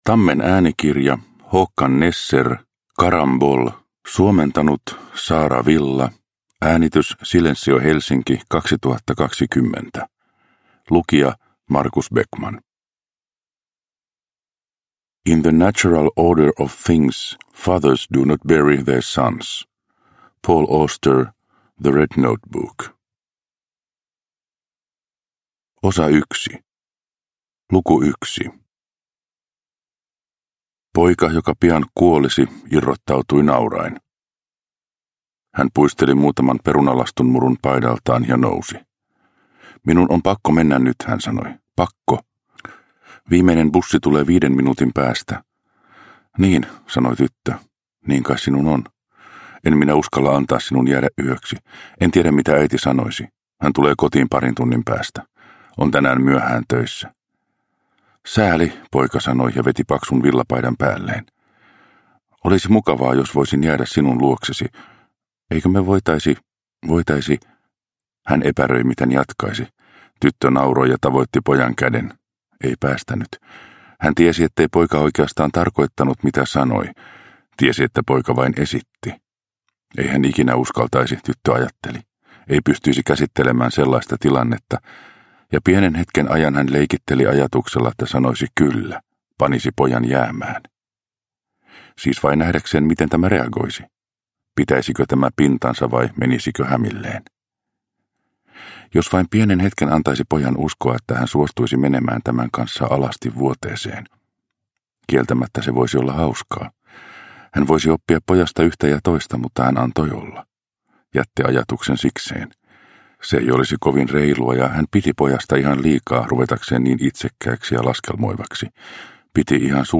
Carambole – Ljudbok – Laddas ner